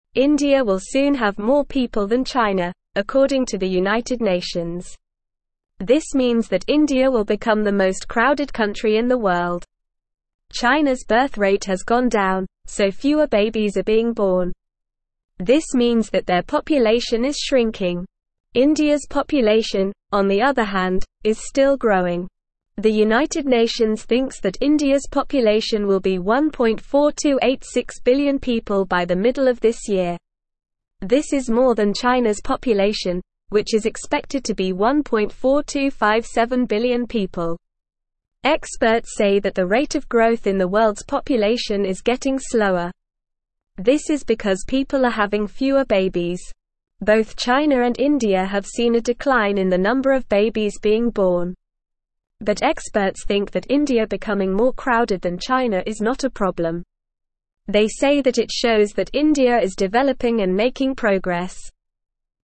Normal
English-Newsroom-Beginner-NORMAL-Reading-India-to-Become-Most-Crowded-Country-Soon.mp3